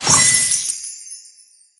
become_chips_01.ogg